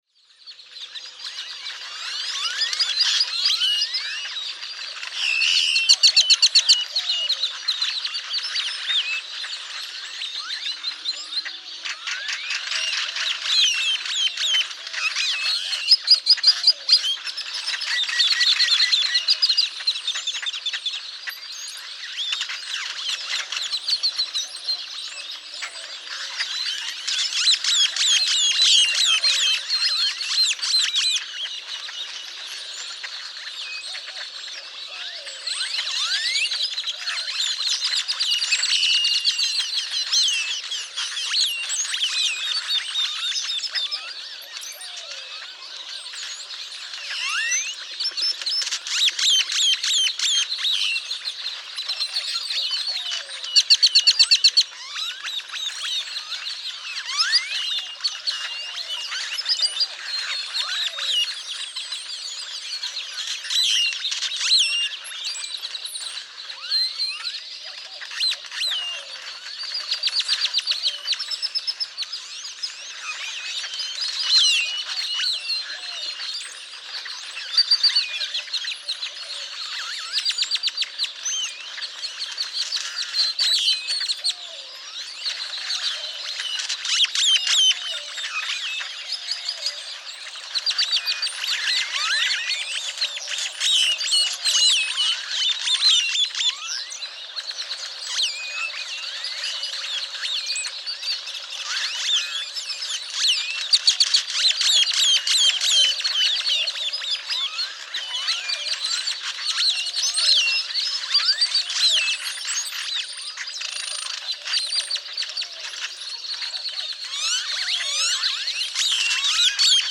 Great-tailed grackle
Sounds of the nonbreeding birds awaking in an overnight, communal roost.
Bandera, Texas.
459_Great-tailed_Grackle.mp3